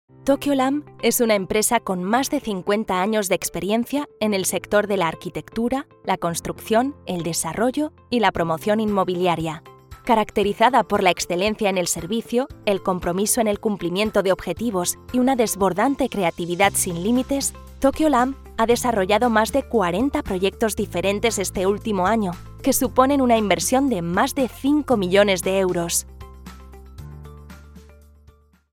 Kommerziell, Junge, Natürlich, Freundlich, Warm
Unternehmensvideo